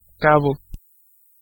Ääntäminen : IPA : /ɛnd/